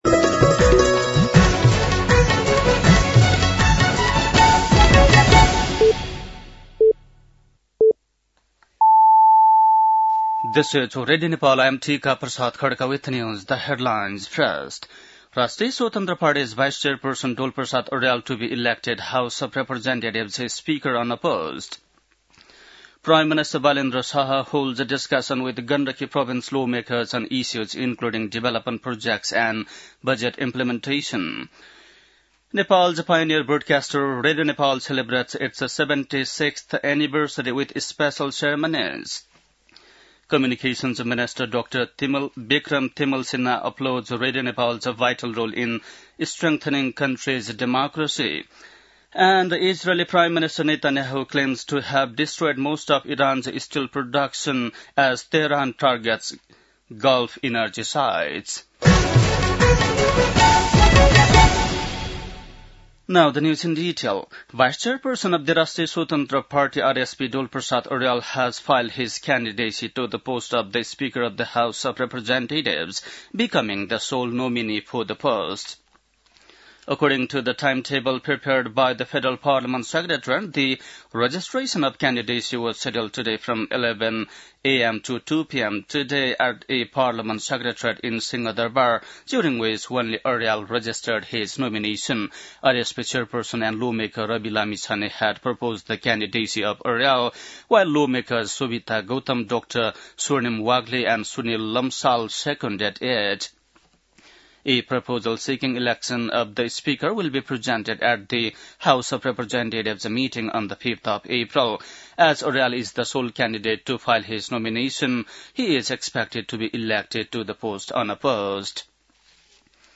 बेलुकी ८ बजेको अङ्ग्रेजी समाचार : २० चैत , २०८२
8-PM-english-NEWS-12-20.mp3